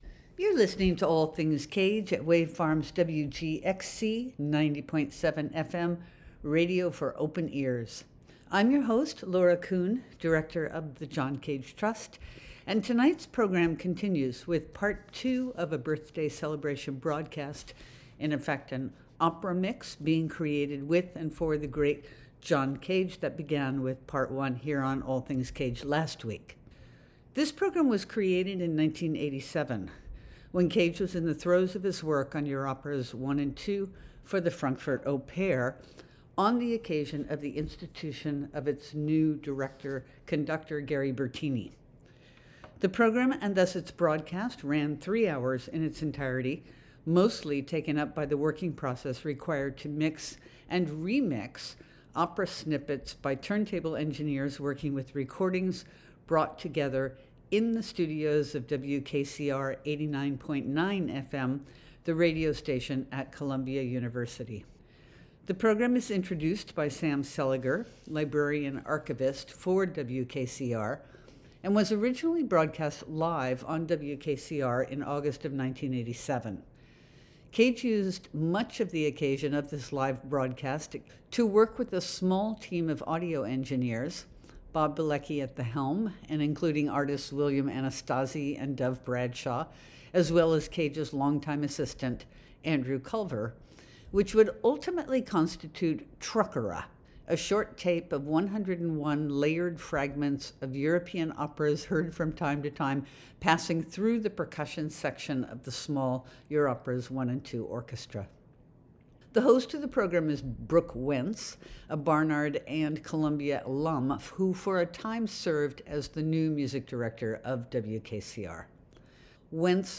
This program was created in 1987, when Cage was in the throes of his work on Europeras 1 & 2 for the Frankfurt Oper on the occasion of the institution of its new director/conductor, Gary Bertini. The program and thus its broadcast ran three hours in its entirety, mostly taken up by the working process required to mix and remix opera snippets by turntable engineers working with recordings brought together in the studios of WKCR 89.9-FM, the radio station at Columbia University.